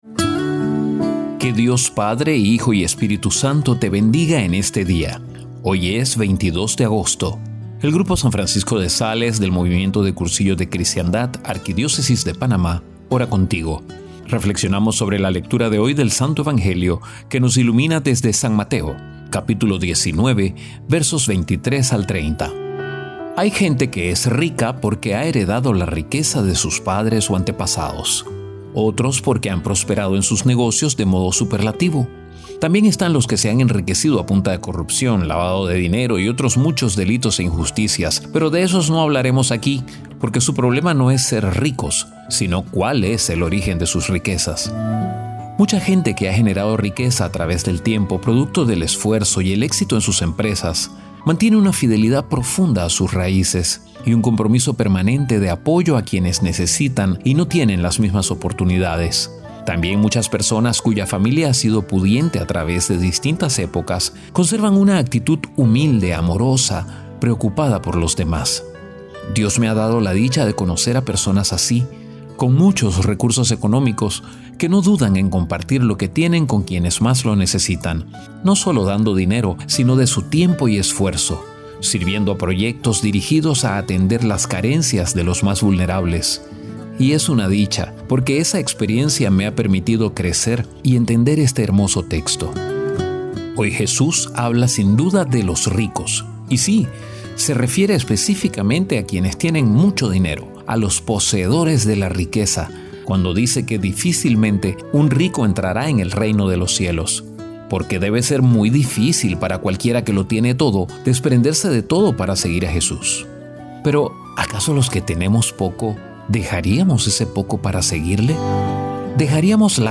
A  continuación la audioreflexión preparada por el grupo «San Francisco de Sales» del Movimiento de Cursillos de Cristiandad de la Arquidiócesis de Panamá, junto a una imagen para ayudarte en la contemplación.